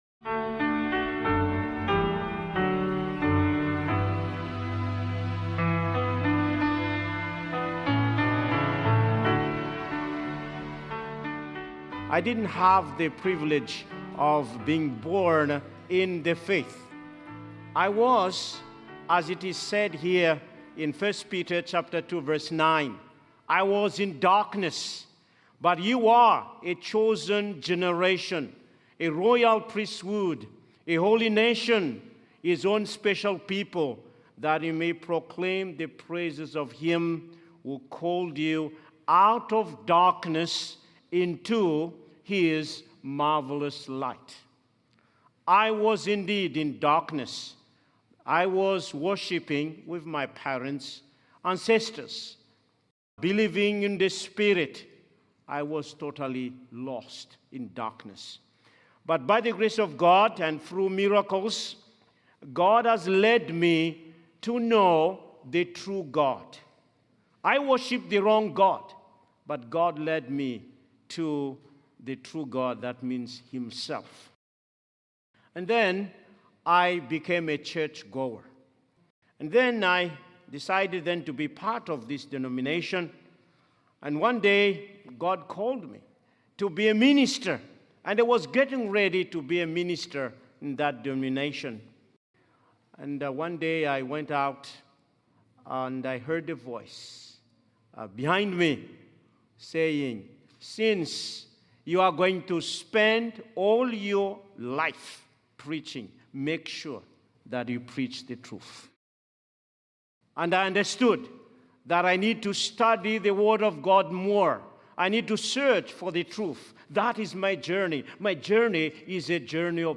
This sermon is a powerful call to live with urgency, reminding believers that life is brief and true faith must be actively pursued through prayer, surrender, and daily commitment. Through personal testimony, scripture, and prophetic insight, it challenges us to embrace our identity, avoid spiritual procrastination, and live each day as if it were our last.